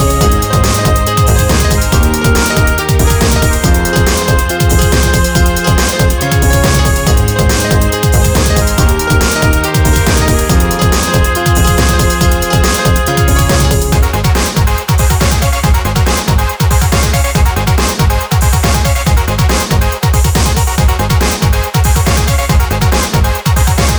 no Backing Vocals Dance 4:11 Buy £1.50